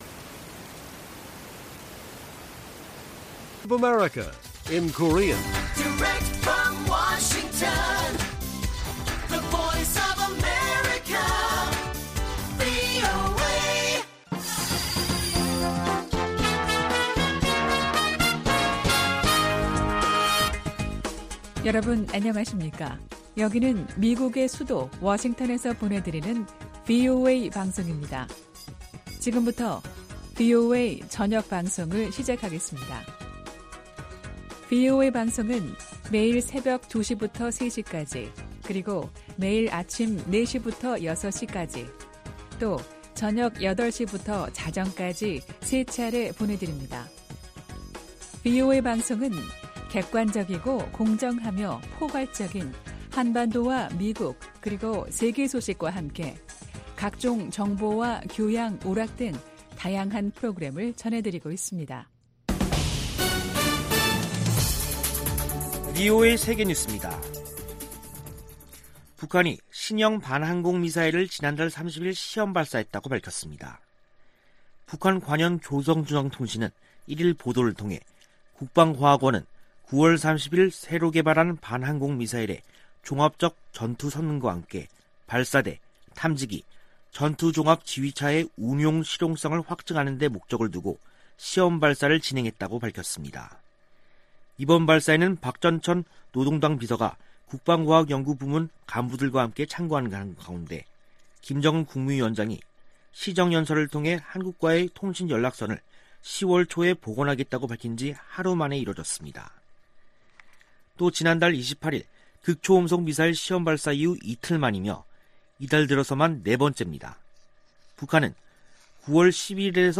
VOA 한국어 간판 뉴스 프로그램 '뉴스 투데이', 2021년 10월 1일 1부 방송입니다. 북한은 김정은 국무위원장이 남북 통신연락선 복원 의사를 밝힌 이튿날 신형 지대공 미사일을 시험발사했습니다. 토니 블링컨 미 국무장관은 북한의 극초음속 미사일 시험발사가 유엔 안보리 결의 위반이라고 지적했습니다. 미국의 전문가들은 김정은 위원장 시정연설에 대해 미국과 핵 문제를 협상할 의사가 없는 게 명백하다고 분석했습니다.